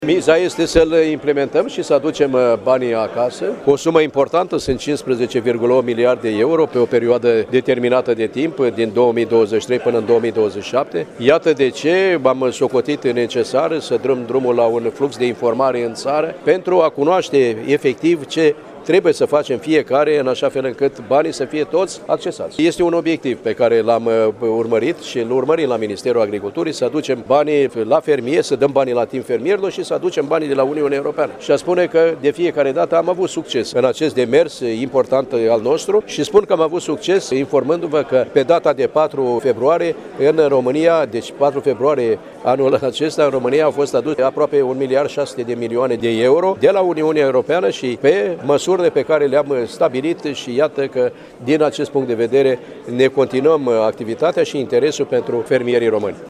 Iaşi – Ministrul Agriculturii, Petre Daea, s-a întîlnit cu fermieri şi agricultori din judeţ
Astăzi, la Iaşi, ministrul Agriculturii, Petre Daea, a prezentat oportunităţile de finanţare prin intermediul Planului Naţional Strategic 2023 – 2027, organizat de reţeaua naţională de dezvoltare rurală.
Ministrul Petre Daea a precizat, în faţa fermierilor şi agricultorilor, că, în următorii cinci ani, România va beneficia de 15,8 miliarde de euro, fonduri care vor veni de la Uniunea Europeană.